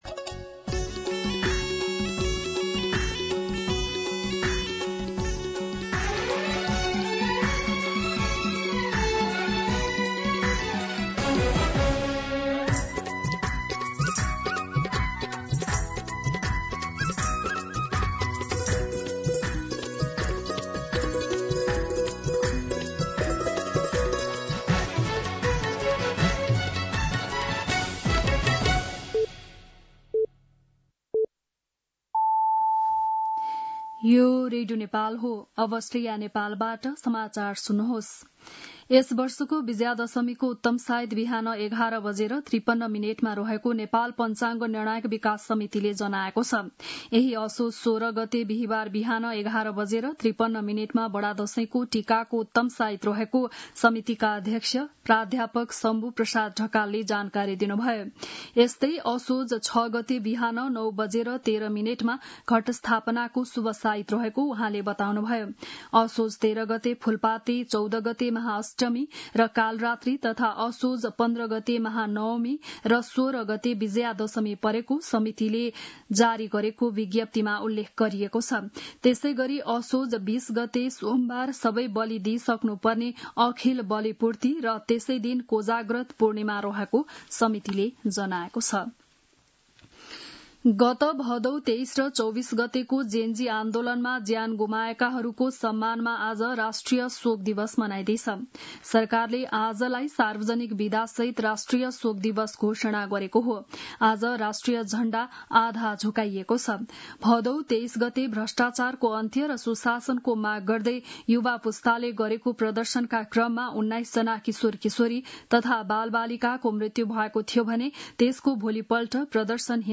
बिहान ११ बजेको नेपाली समाचार : १ असोज , २०८२